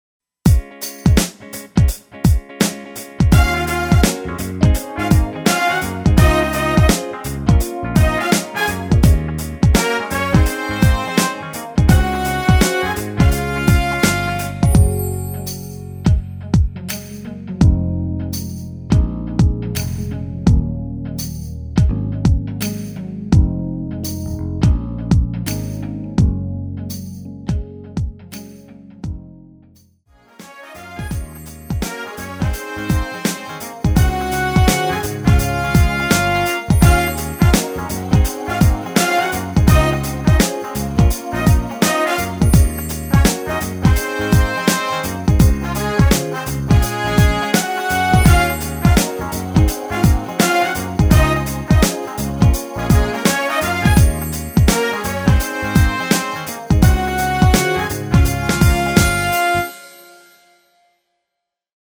랩 부분 삭제한 MR입니다.
전주가 길어서 짧게 편곡 하였으며 브라스 악기 끝나고 15초쯤 노래 들어 가시면 됩니다.
Db
앞부분30초, 뒷부분30초씩 편집해서 올려 드리고 있습니다.
중간에 음이 끈어지고 다시 나오는 이유는